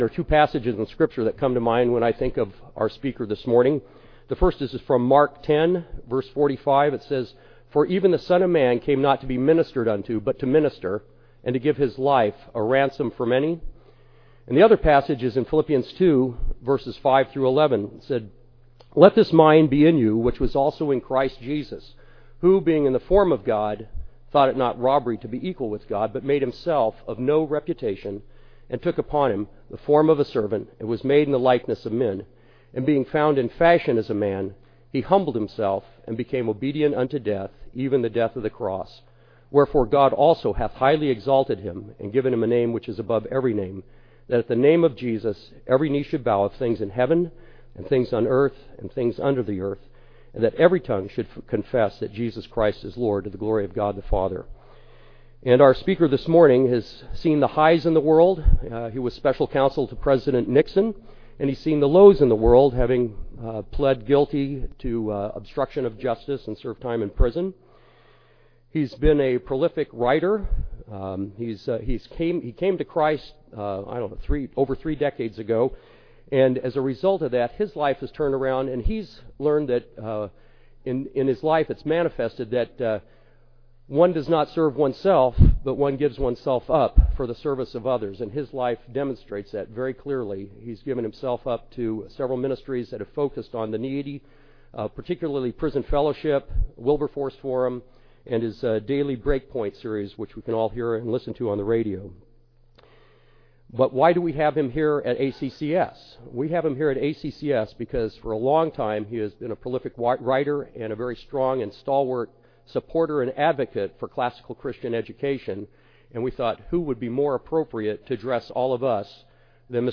2009 Plenary Talk | 0:53:07 | All Grade Levels, Culture & Faith
Mar 11, 2019 | All Grade Levels, Conference Talks, Culture & Faith, Library, Media_Audio, Plenary Talk | 0 comments